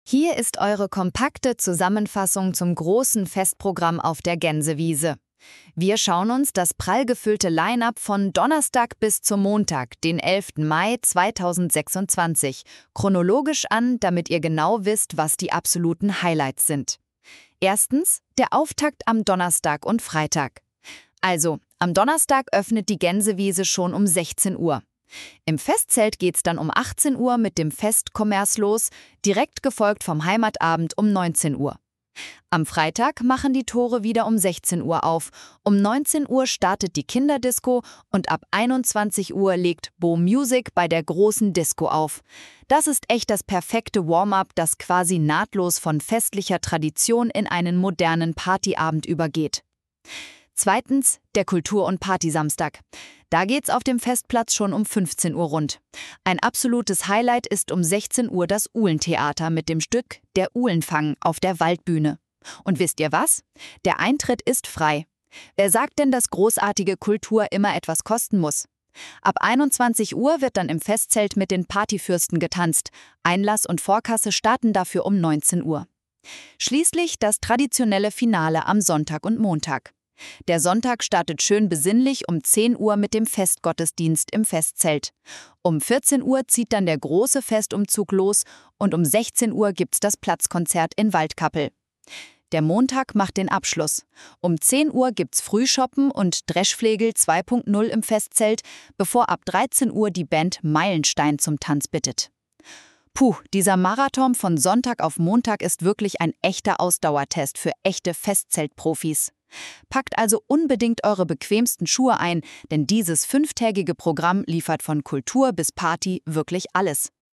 Mit Hilfe der KI, da wird die Stimme schöner, hier das